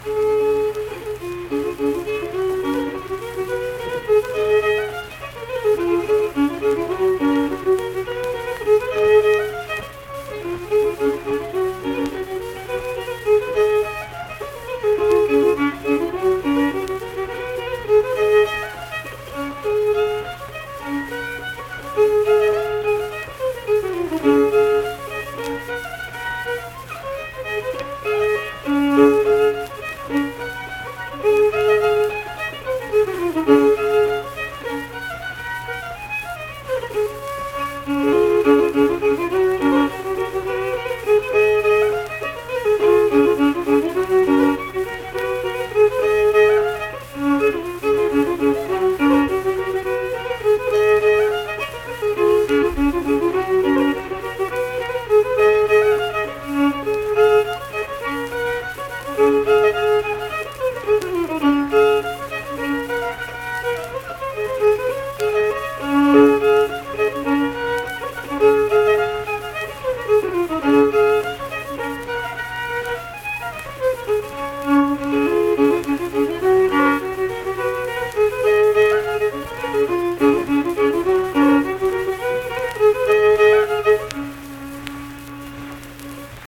Unaccompanied fiddle music
Verse-refrain 2(1).
Performed in Ziesing, Harrison County, WV.
Instrumental Music
Fiddle